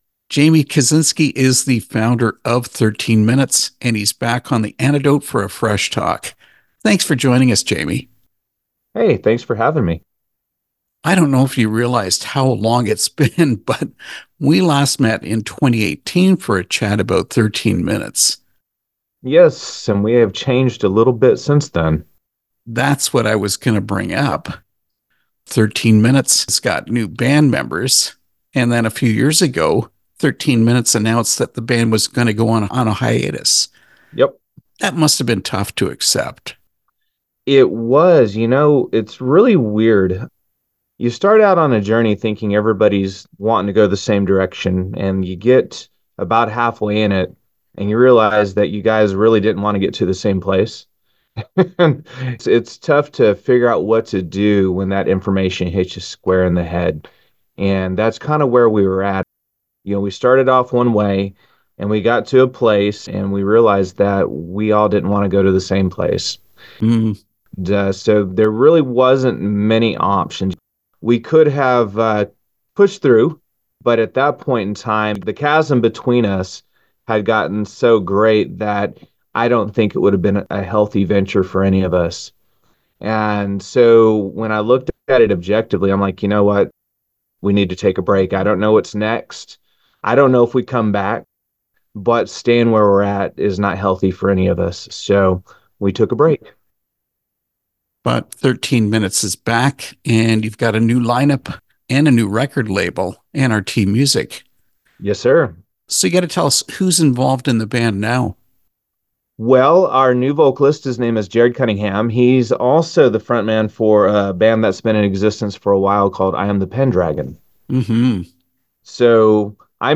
Interview with XIII Minutes (2025)
Xlll-Minutes-interview-2025.mp3